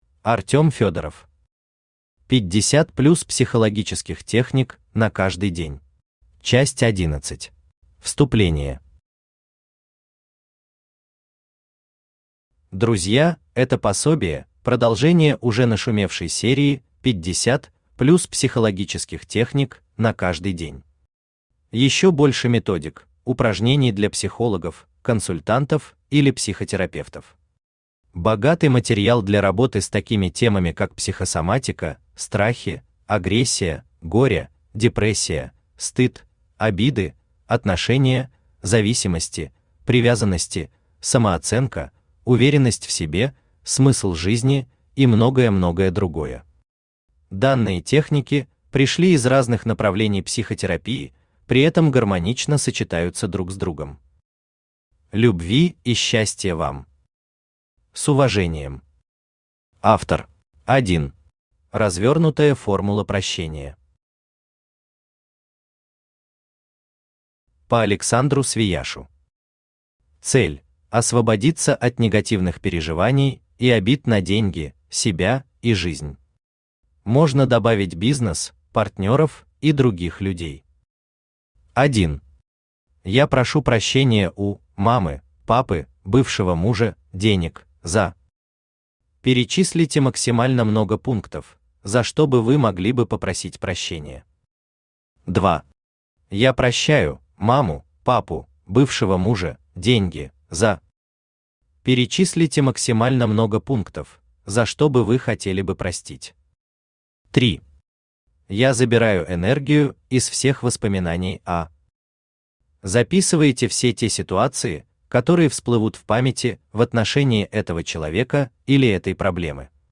Аудиокнига 50+ психологических техник на каждый день. Часть 11 | Библиотека аудиокниг
Aудиокнига 50+ психологических техник на каждый день. Часть 11 Автор Артем Иванович Федоров Читает аудиокнигу Авточтец ЛитРес.